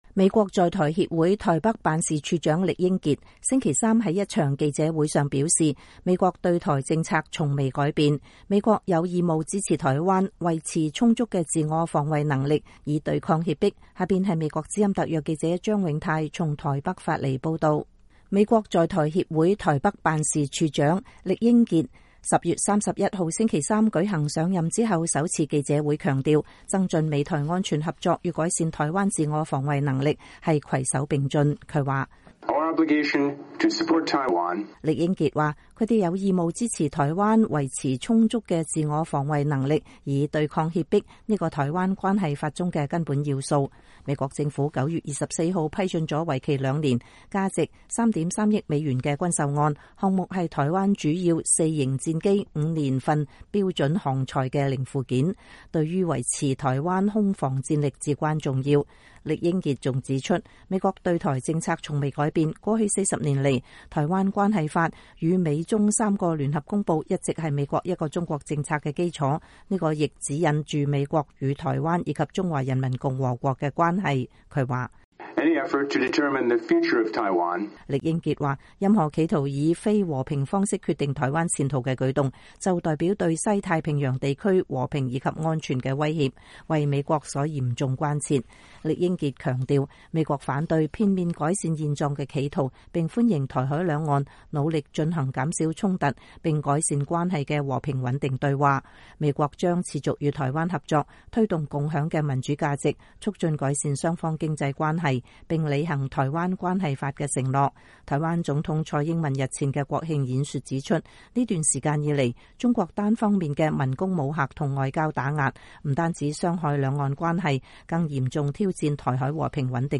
美國在台協會台北辦事處長酈英傑星期三在一場記者會上表示，美國對台政策從未改變，美國有義務支持台灣維持充足的自我防衛能力以對抗脅迫。
酈英傑處長能說一口流利的中文，今年8月份到任，這也是他外交生涯當中第3度派駐台灣。